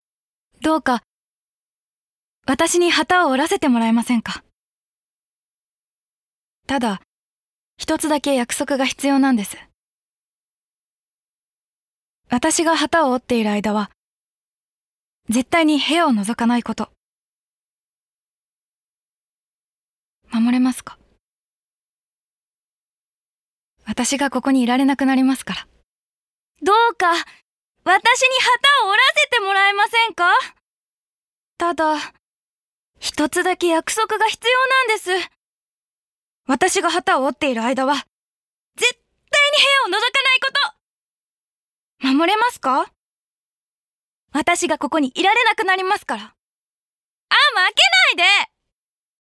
ボイスサンプル
CMナレーション